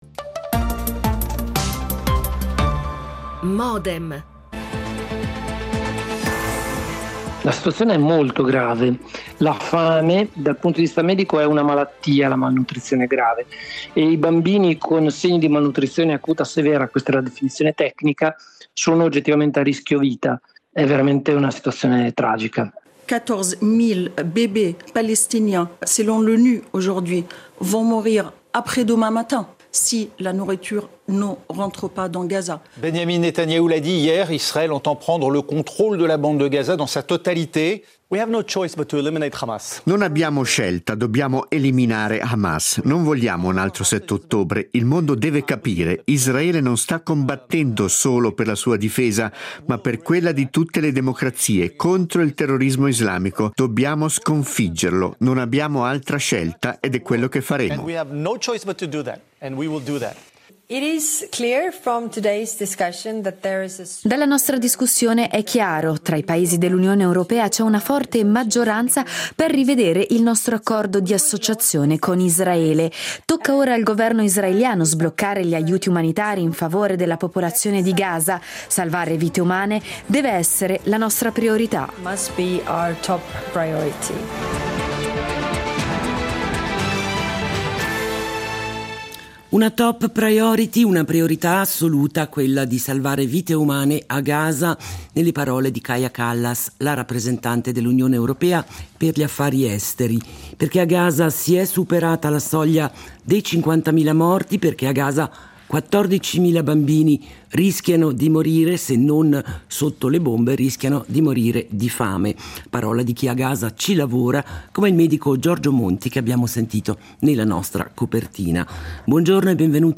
Sui sussulti della comunità internazionale e sulla situazione a Gaza Modem vi propone una discussione con:
L'attualità approfondita, in diretta, tutte le mattine, da lunedì a venerdì